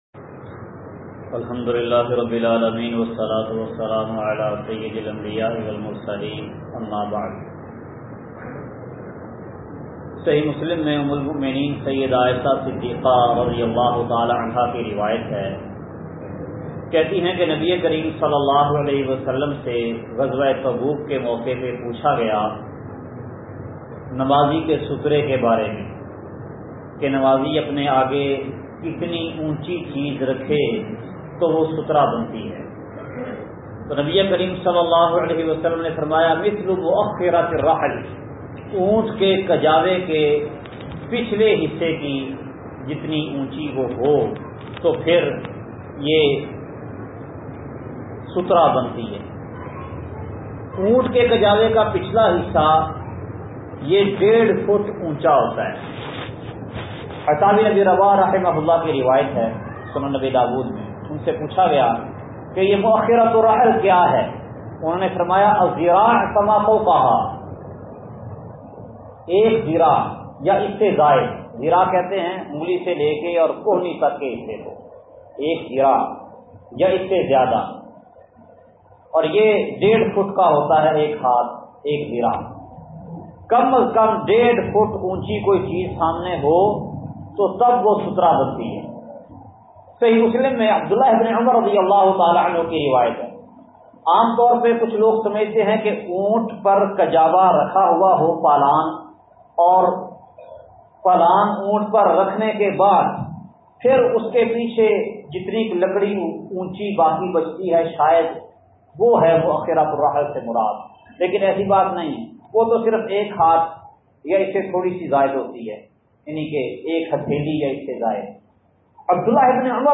درس کا خلاصہ